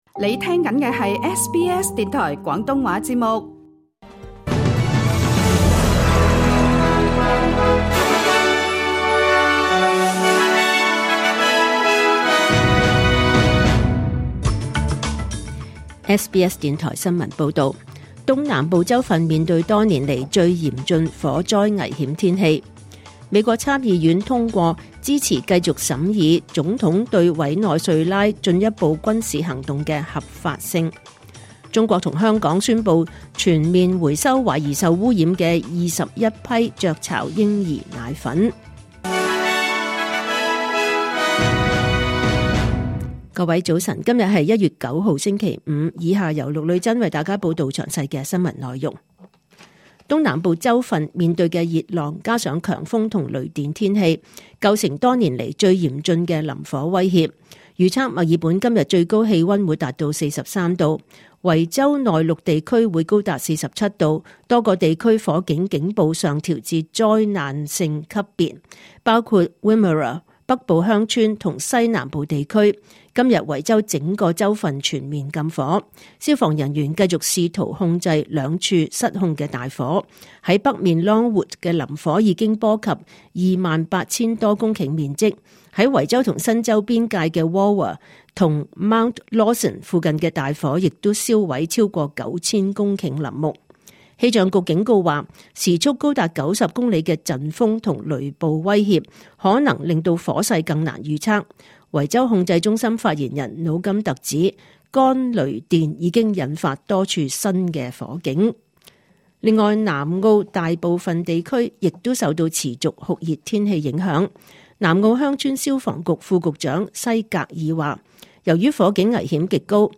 2026 年 1 月 9 日 SBS 廣東話節目九點半新聞報道。